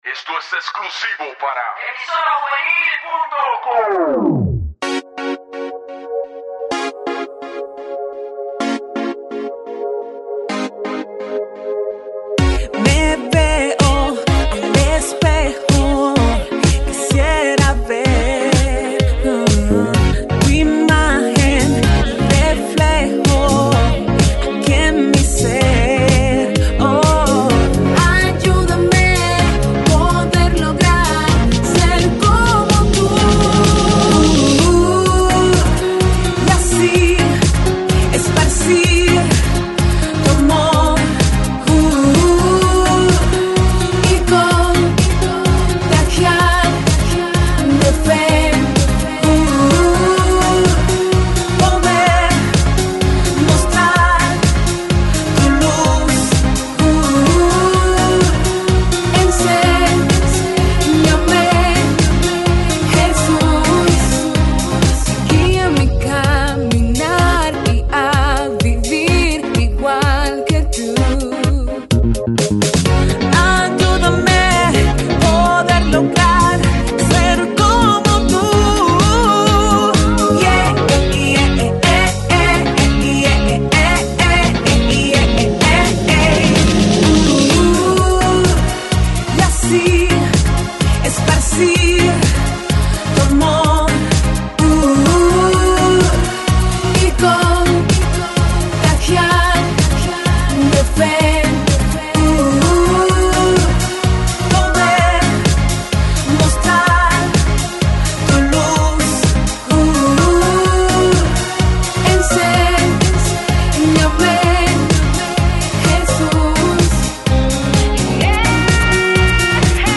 Música Cristiana